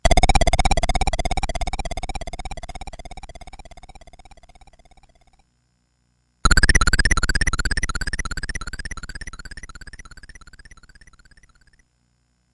描述：从Atari ST录制的声音效果
Tag: 雅达利 Soundeffects 芯片音乐 YM2149 电子